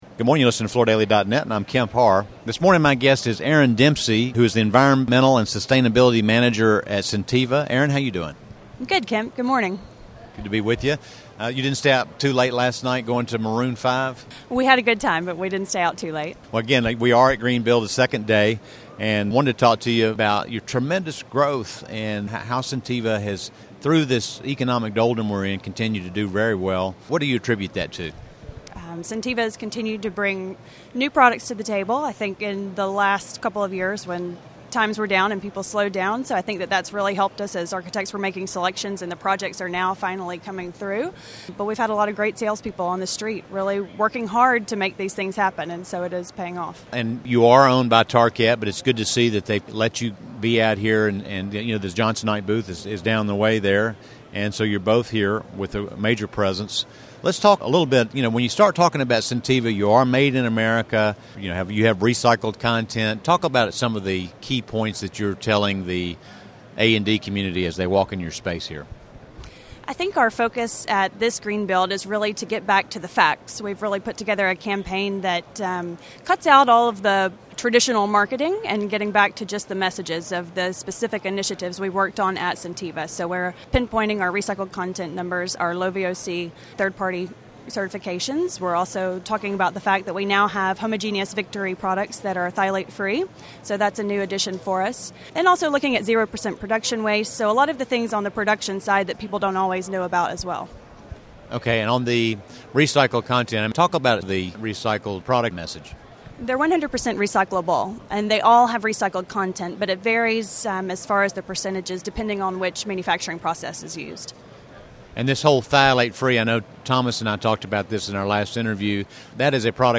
at Greenbuild 2011